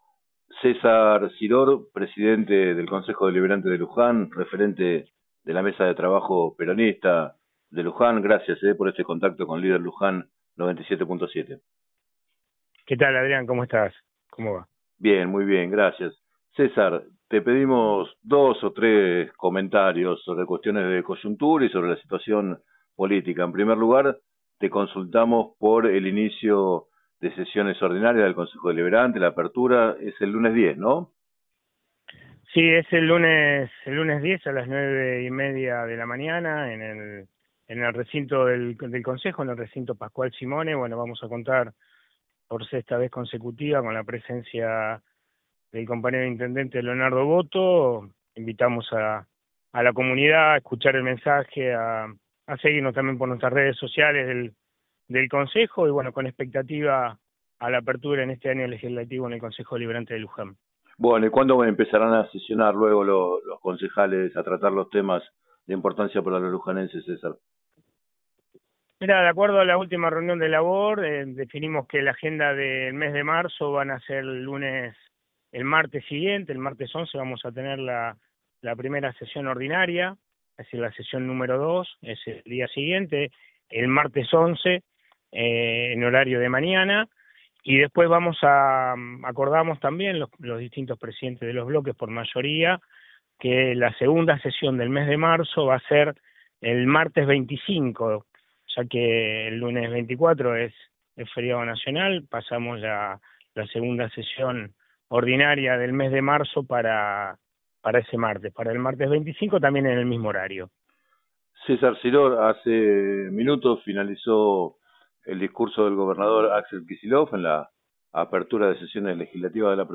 En declaraciones a Líder Luján Noticias, Siror consideró que un acuerdo en términos programáticos entre la presidenta del Partido Justicialista, Cristina Fernández de Kirchner, y el gobernador de la Provincia de Buenos Aires, Axel Kicillof, “nos liberaría de una discusión absurda”.